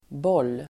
Uttal: [bål:]